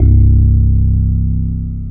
Index of /90_sSampleCDs/Syntec - Wall of Sounds VOL-2/JV-1080/SMALL-PIANO